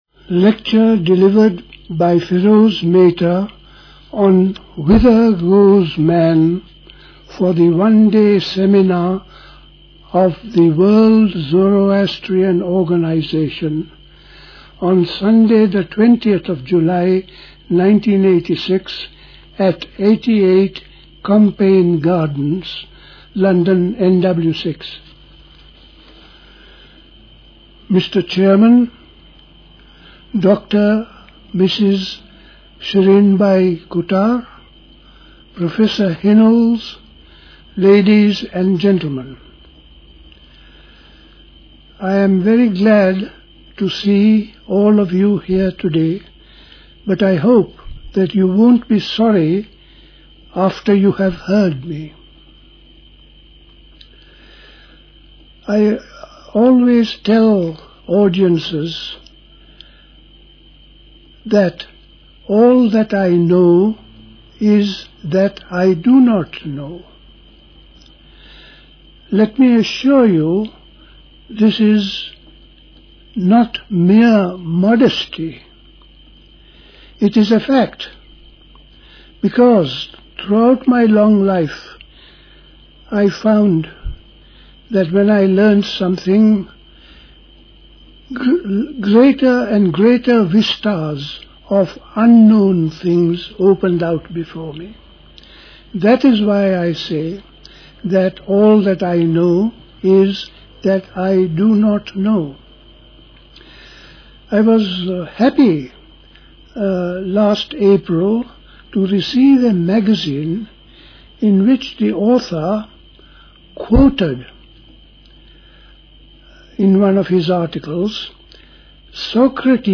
Recorded at the World Zoroastrian Organisation Seminar.